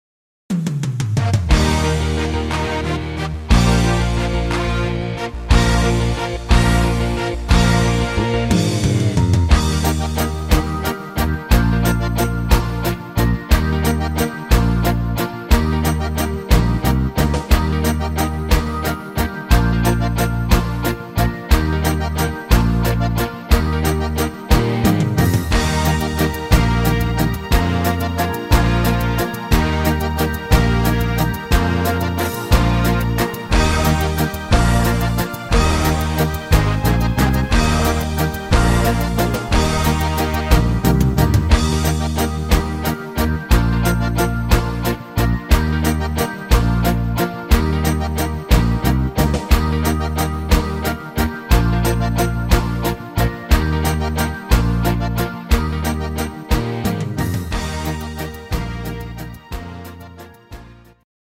Geburtstagslied